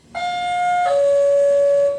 Sound Effects
Doorbell Pa System